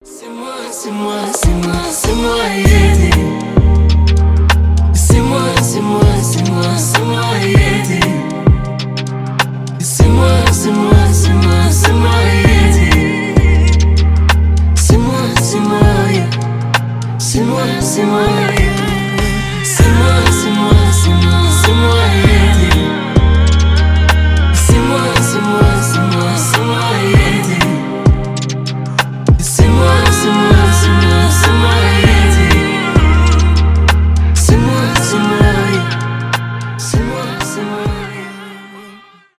поп , романтические
дуэт